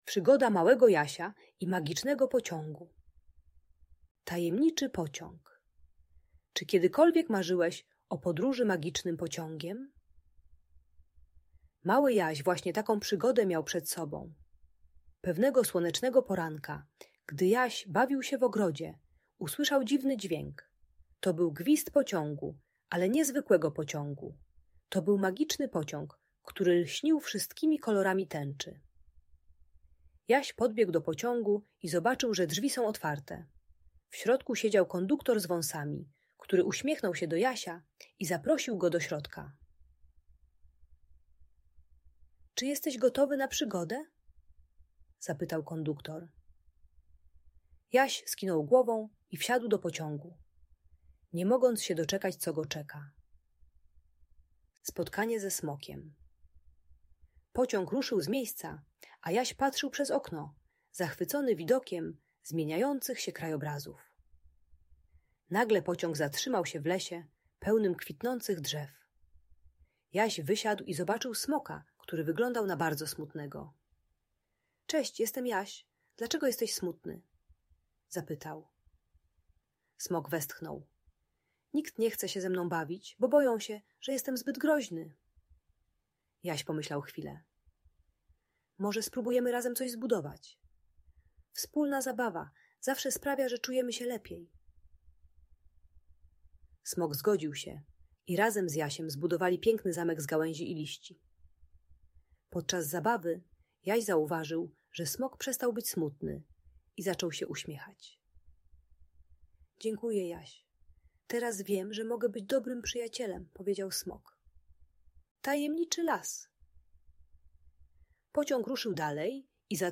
Przygoda Małego Jasia i Magicznego Pociągu - Audiobajka